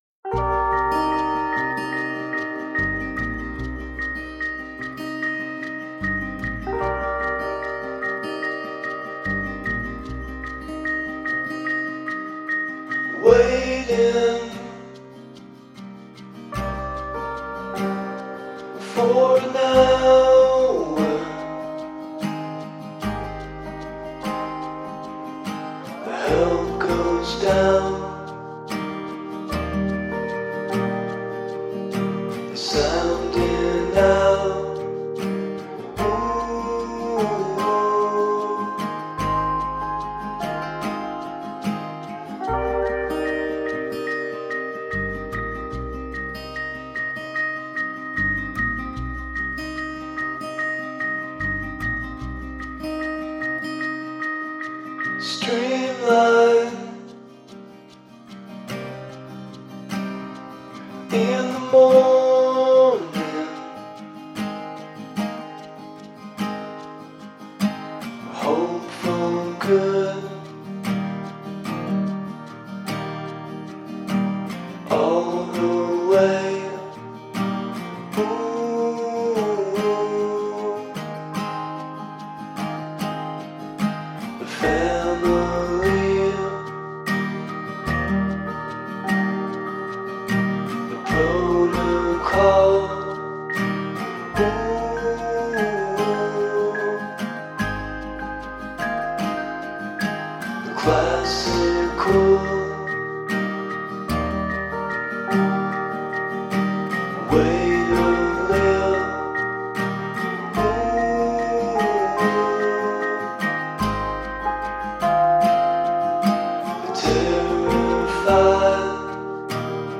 Melody-driven indie-folk.
Tagged as: Alt Rock, Folk-Rock, Chillout, Indie Rock